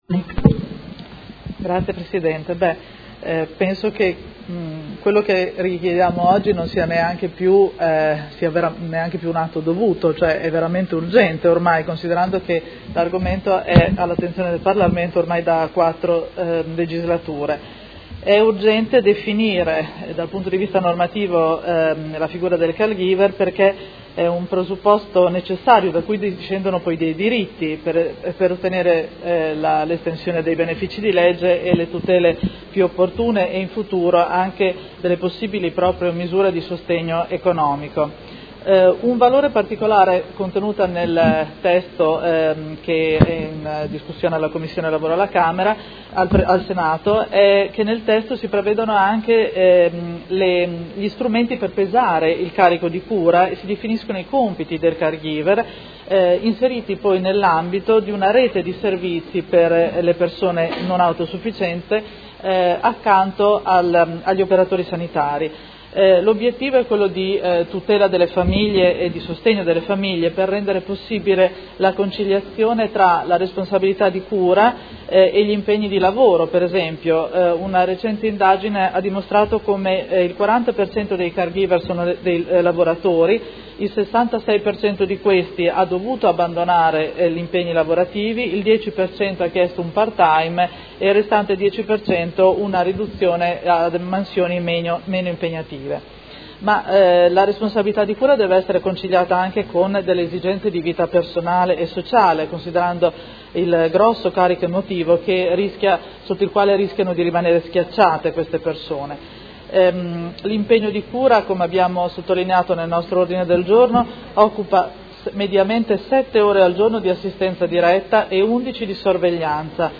Seduta dell'11/05/2017 Dibattito. Ordini del giorno sul "Caregiver familiare"